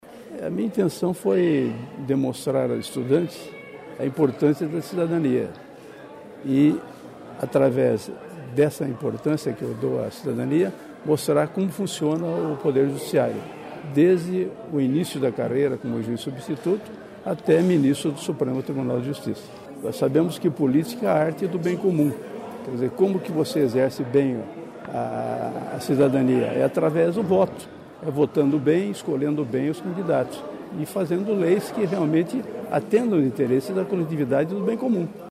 O desembargador aposentado Acácio Cambi falou sobre a atuação do Tribunal de Justiça do Paraná e destacou a importância do exercício da cidadania. Confira a entrevista.